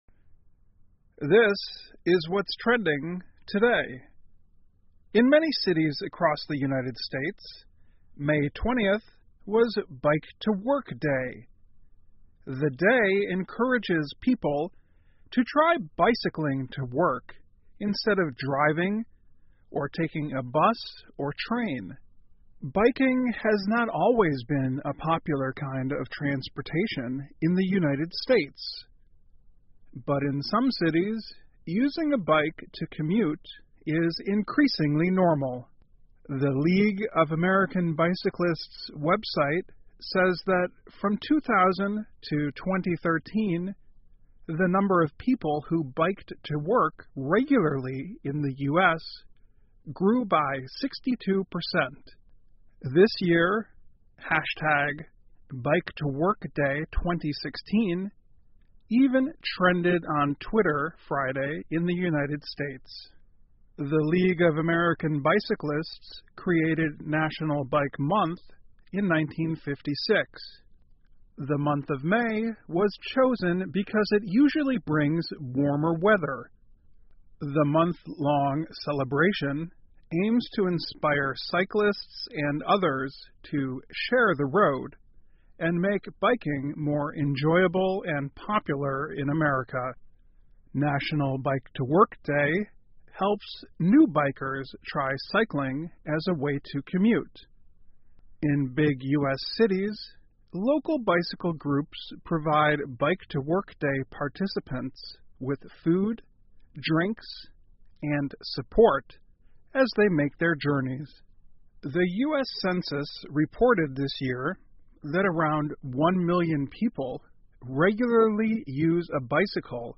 VOA慢速英语2016 美国城市庆祝骑自行车上班日 听力文件下载—在线英语听力室